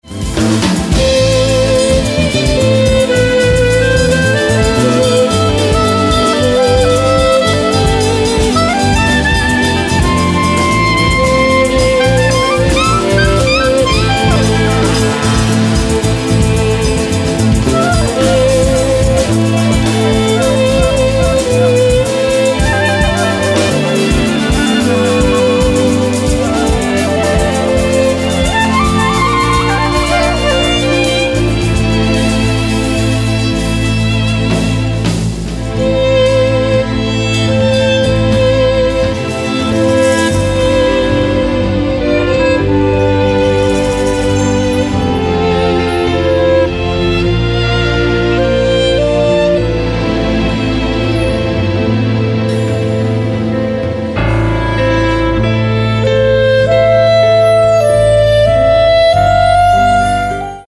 Live au FMPM 2006: